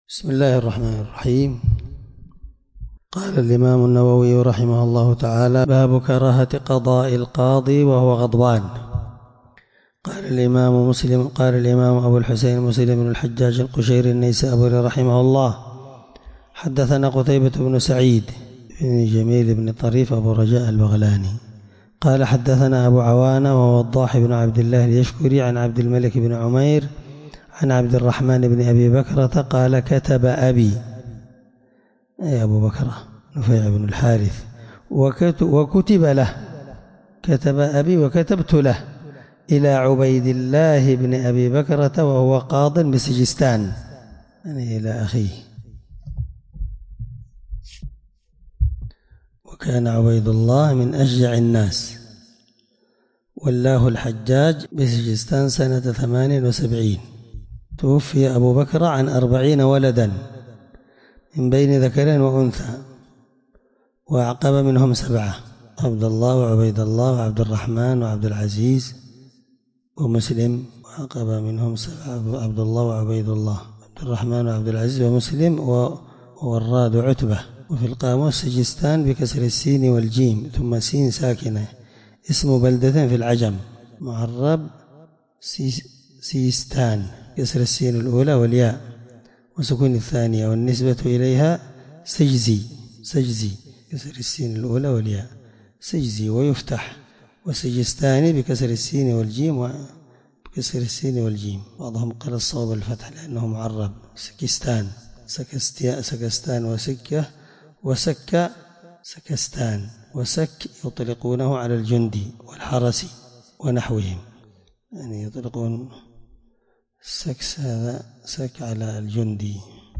الدرس8من شرح كتاب الأقضية الحدود حديث رقم(1717) من صحيح مسلم